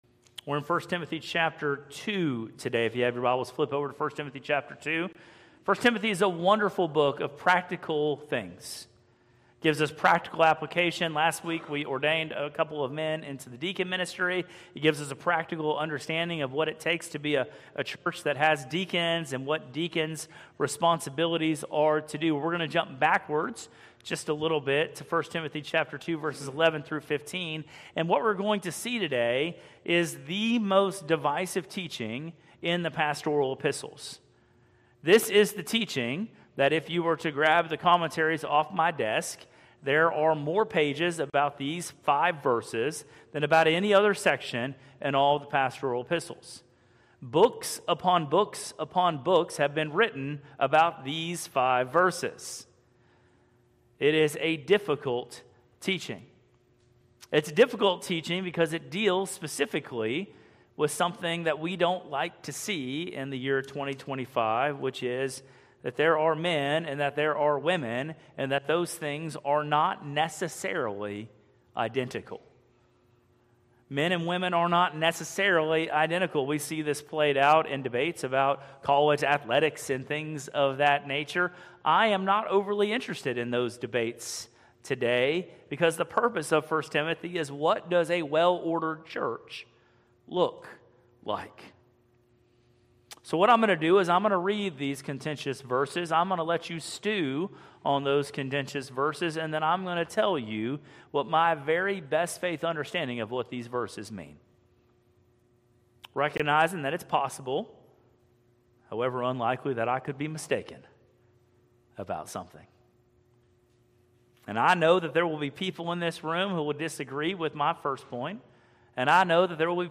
Live-Worship_-91425.mp3